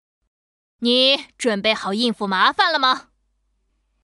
国语少年素人 、女动漫动画游戏影视 、看稿报价女游11 国语 女声 游戏 守望先锋全女英雄模仿-3秩序之光 素人